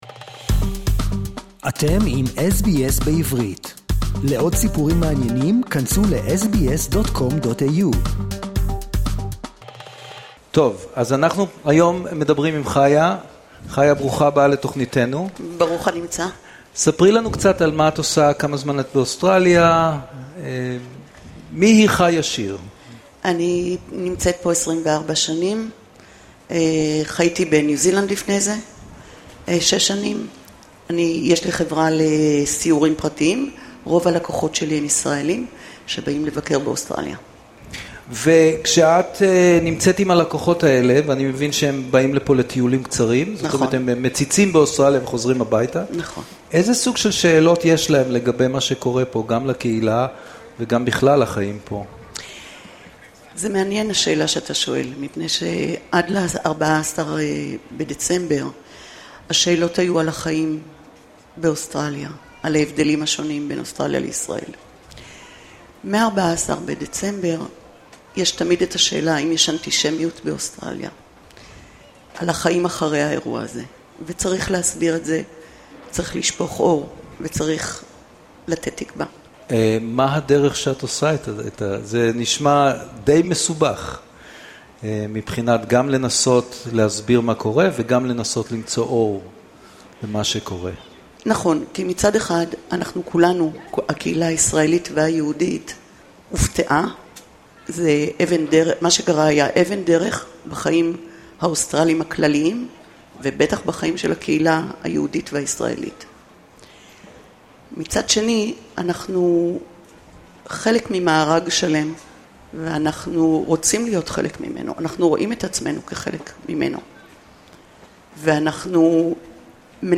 בשבת האחרונה, ב-21 במרץ, כחלק משבוע ההרמוניה, קיימה SBS סדרה מיוחדת של שידורים רב-לשוניים בביתן בונדאי לציון אירוע שנתי זה, הנמשך שבוע שלם, וגם את היום הבינלאומי למיגור האפליה הגזעית.
בביתן בונדאי בסידני במהלך שבוע ההרמוניה 2026